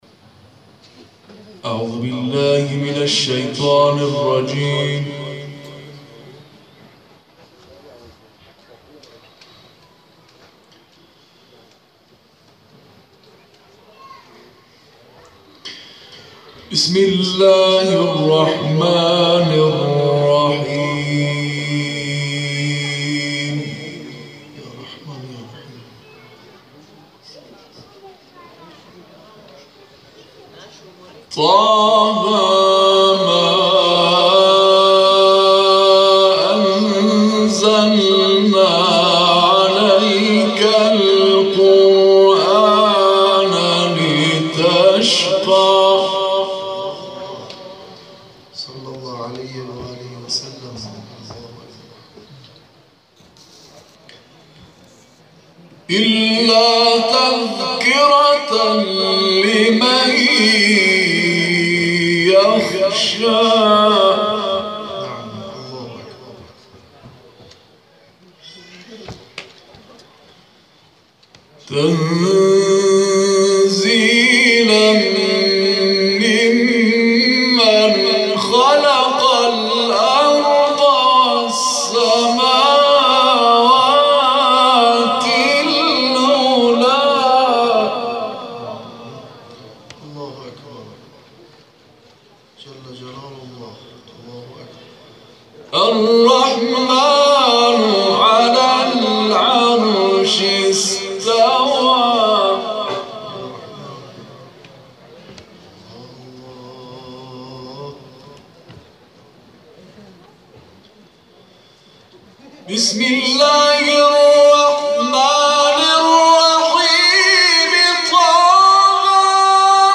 گروه فعالیت‌های قرآنی: محفل انس با قرآن کریم، شب گذشته، 26 خردادماه در مجتمع فرهنگی سرچشمه و حسینیه هدایت تهران برگزار شد.